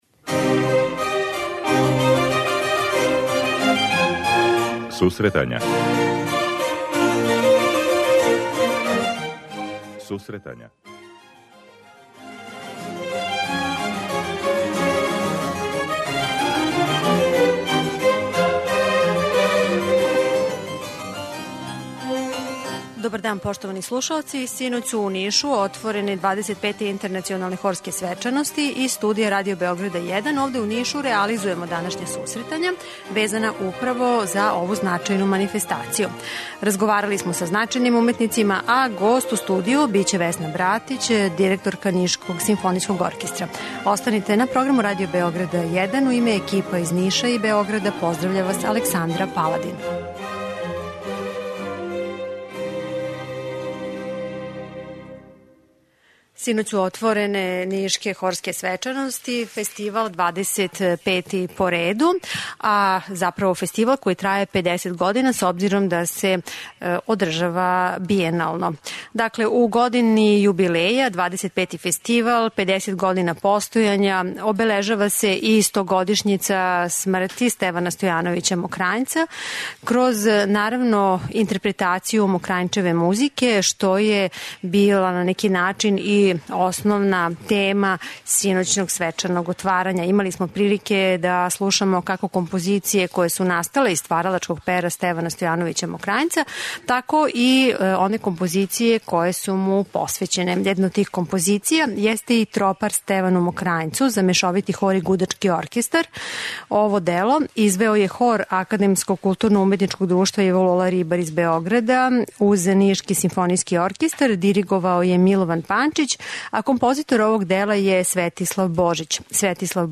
'Интернационалне хорске свечаности' које се одржавају у Нишу окупиле су бројне музичке посленике у овом граду. Теме којима се бавимо везане су за Фестивал, његову педесетогодишњу традицију и значај у српском музичком животу, а емисију реализујемо из града домаћина.